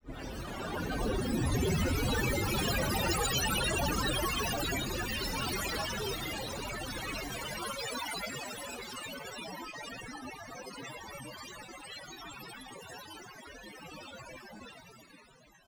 Interactive sound installation
computer_text0.wav